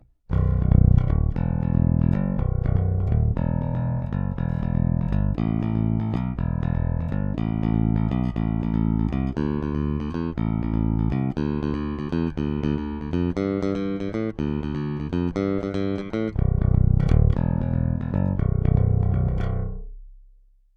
Oba snímače, oba jednocívka (trsátko)